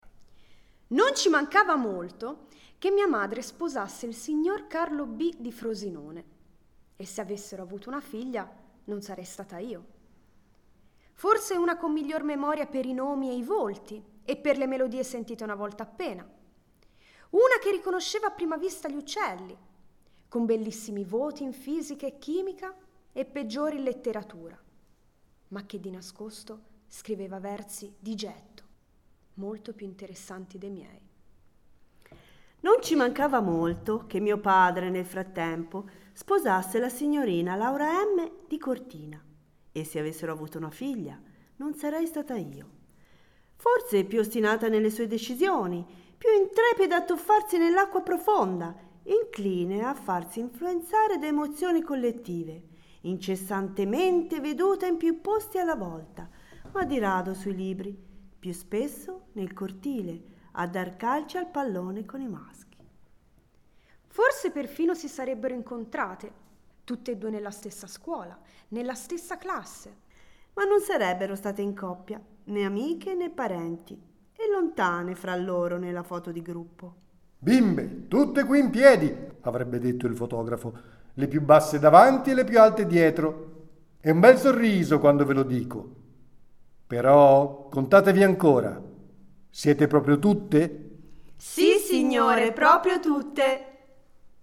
dallo spettacolo del 10 Luglio 2015
Nell’ascolto della recitazione degli attori, diventa esplicita anche un’altra caratteristica comune a molte delle sue poesie, cioè la loro teatralità intrinseca, che forse deriva proprio dall’immediatezza del loro stile: questo permette di interpretarle dando voce e vita direttamente agli stessi protagonisti delle poesie.